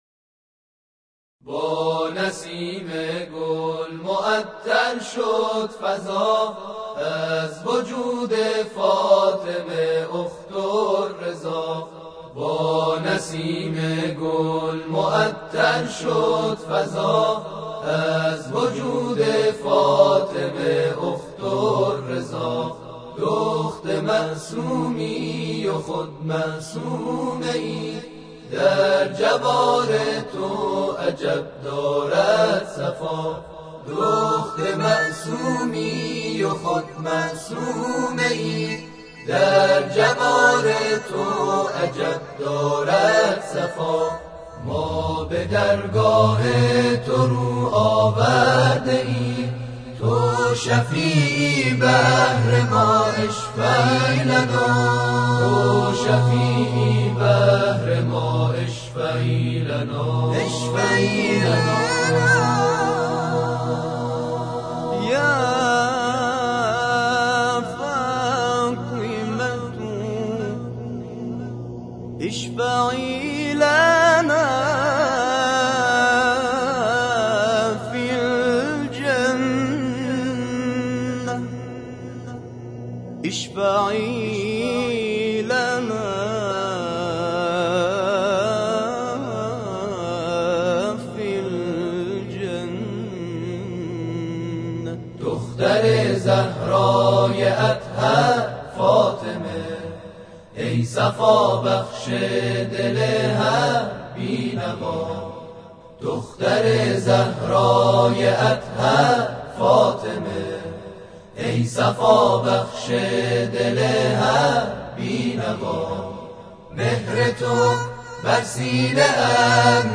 دانلود تواشیح